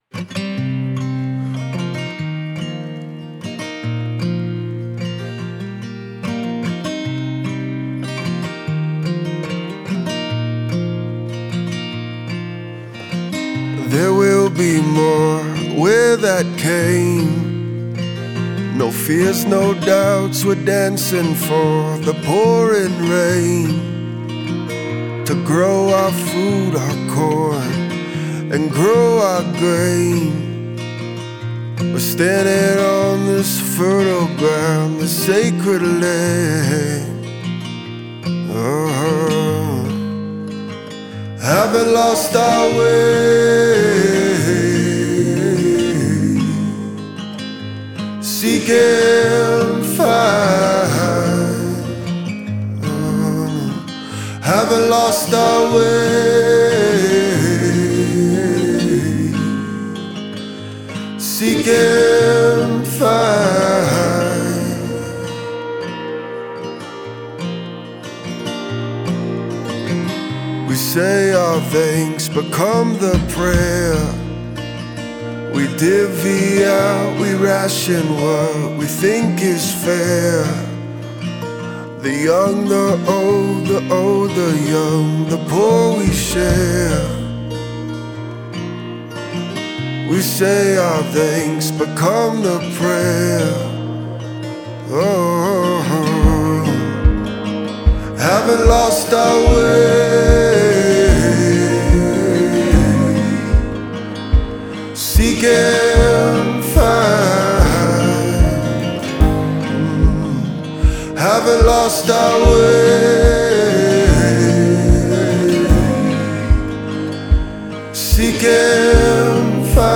contemporary folk / singer-songwriter